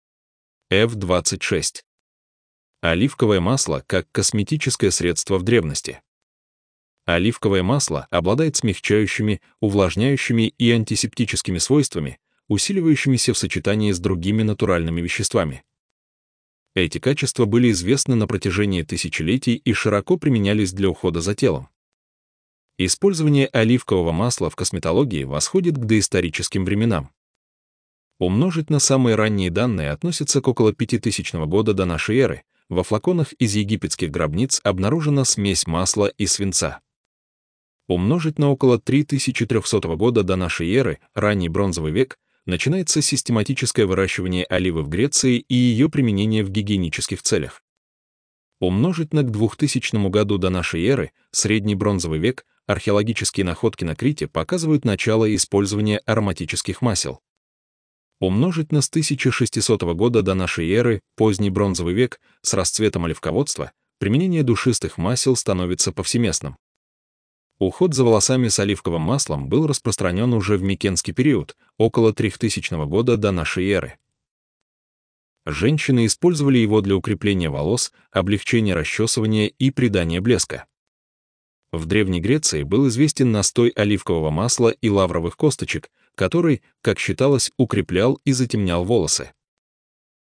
Аудиогид